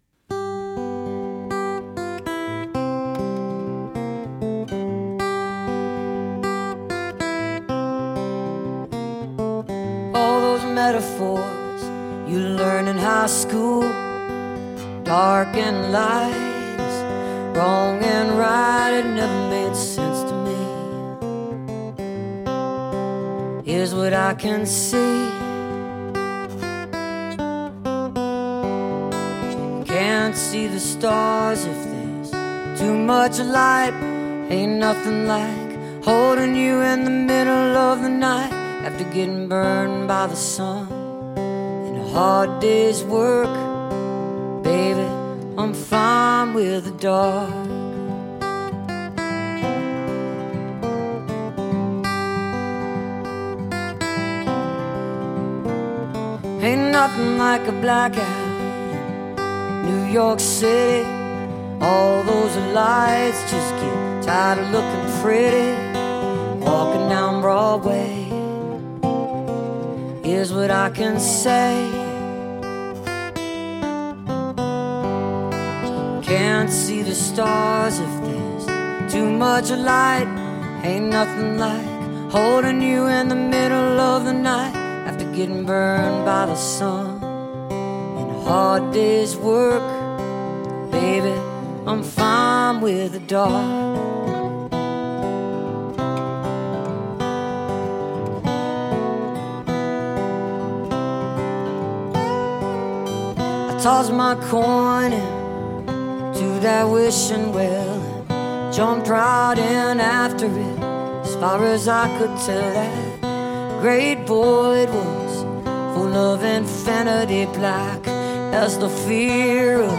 (recorded from a webcast)